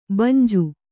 ♪ banju